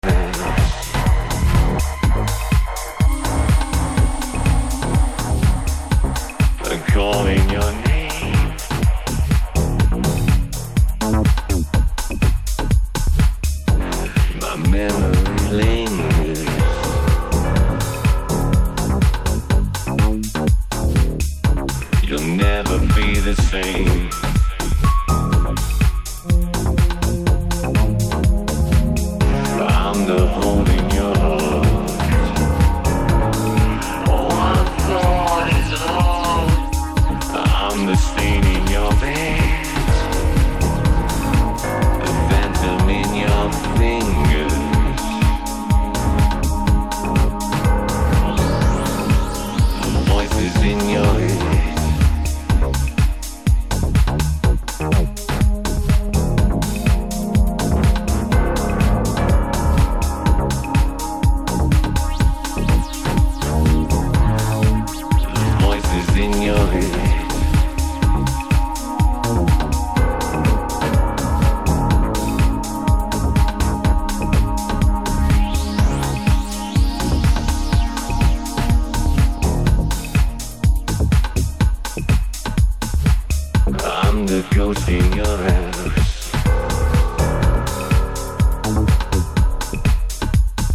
Two great re-working’s of two-synth classics.
Electro House